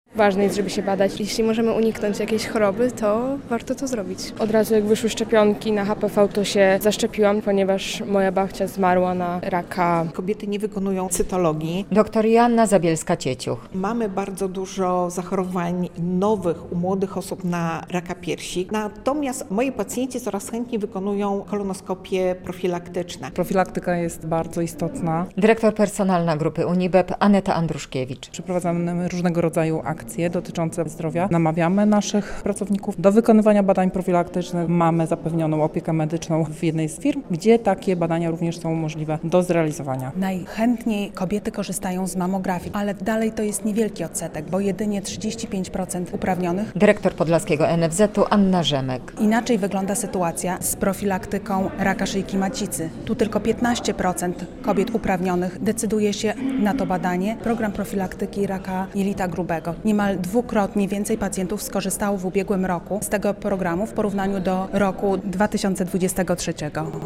Radio Białystok | Wiadomości | Wiadomości - Debata poświęcona profilaktyce onkologicznej na Uniwersytecie Medycznym w Białymstoku